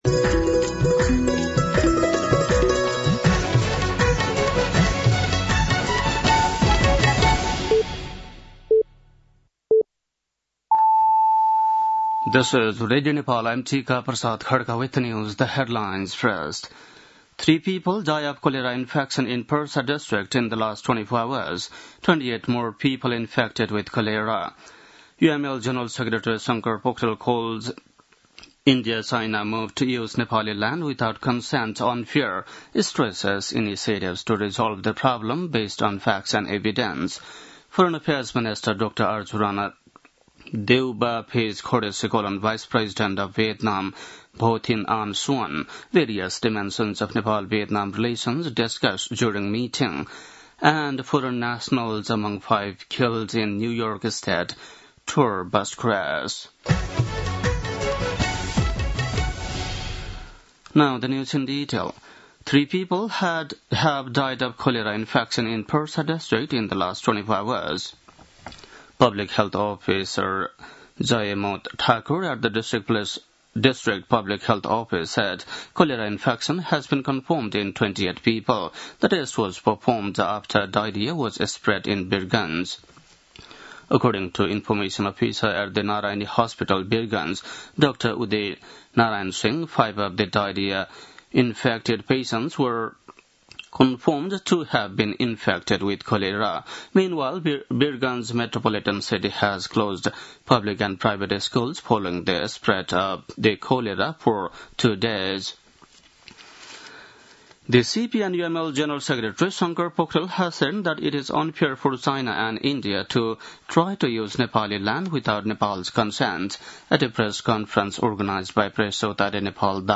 बेलुकी ८ बजेको अङ्ग्रेजी समाचार : ७ भदौ , २०८२